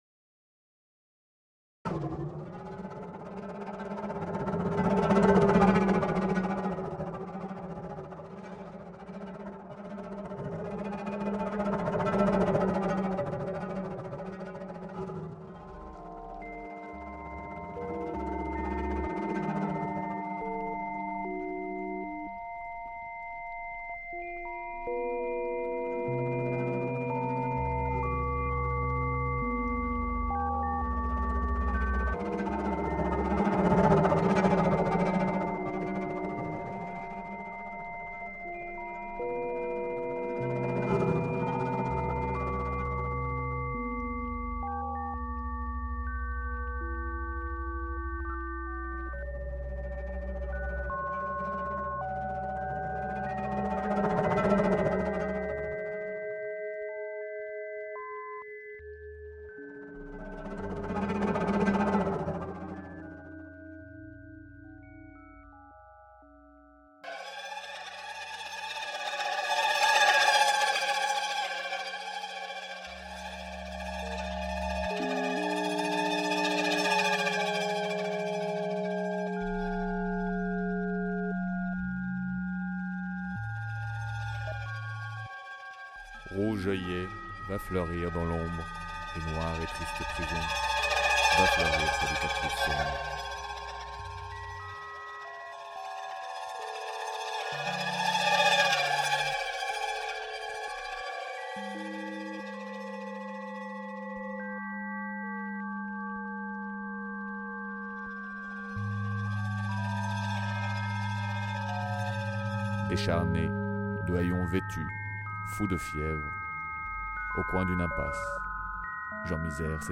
Installation sonore immersive monumentale, diffusée sur 16 haut-parleurs répartis sur cent mètres de la rue de la Fontaine au Roi, Paris 10ème.
Une rivière sonore « coule » le long de la rue de la Fontaine au Roi et « se jette » dans une librairie, faisant référence aux dernières barricades de la Commune et à une ancienne rivière qui alimentait Paris en eau potable.
Réalisé dans le cadre de La Nuit Blanche du Roman Noir, Nuit Blanche Paris 4-5 octobre 2008.
Rouge-Ruisseau-stereo_extrait.mp3